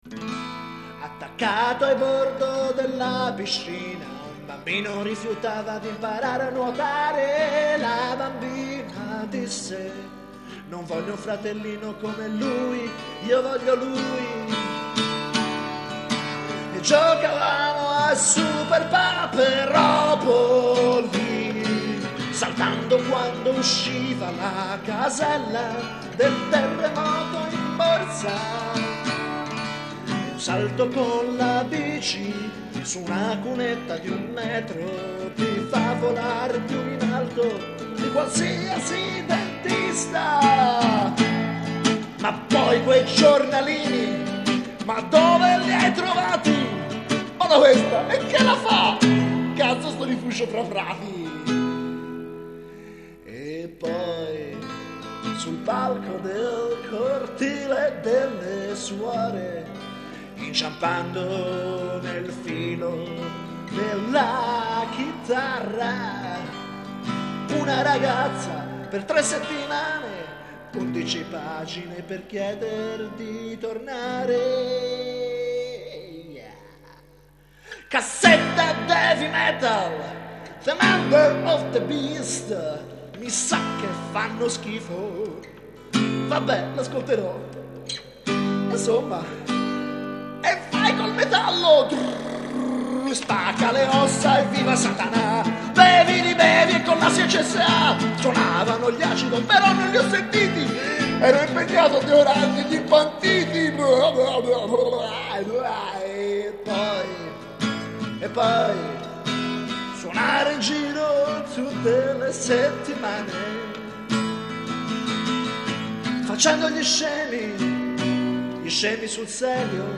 E' incasinato e improvvisato come la mia vita, appunto, oh yeah!